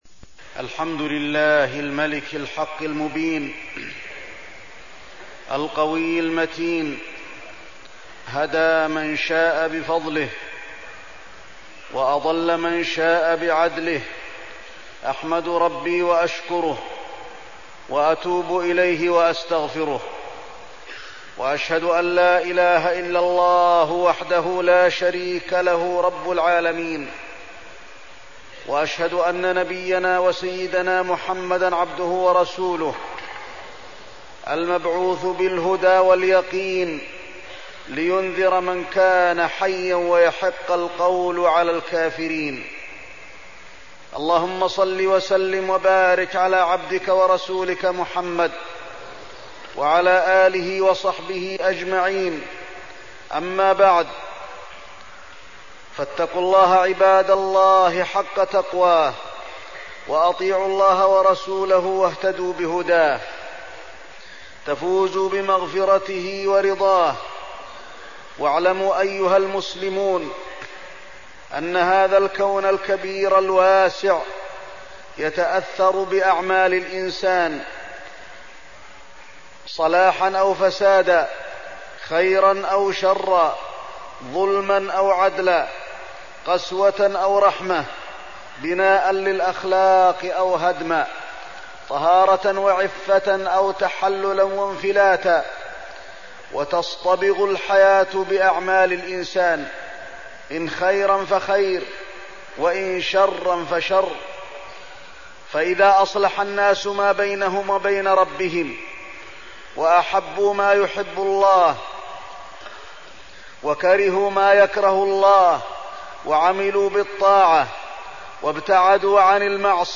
تاريخ النشر ٩ ذو الحجة ١٤١٦ هـ المكان: المسجد النبوي الشيخ: فضيلة الشيخ د. علي بن عبدالرحمن الحذيفي فضيلة الشيخ د. علي بن عبدالرحمن الحذيفي آثار الطاعة والمعصية